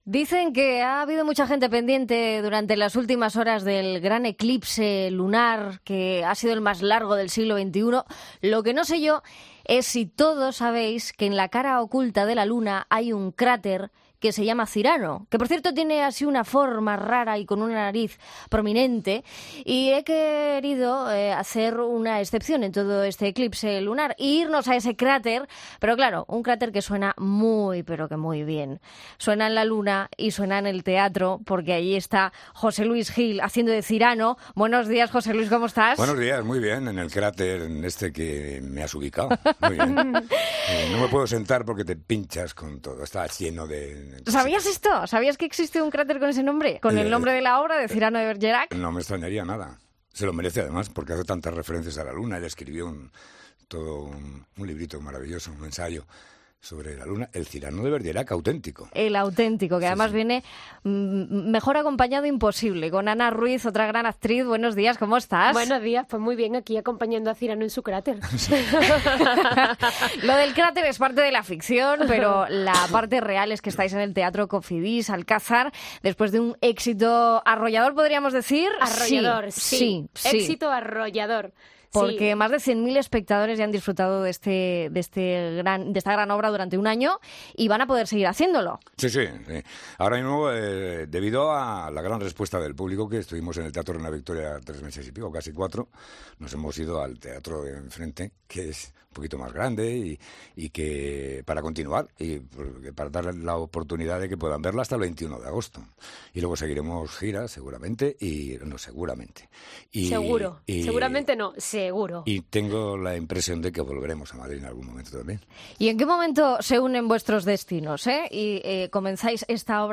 Entrevista al actor José Luis Gil por la obra Cyrano de Berguerac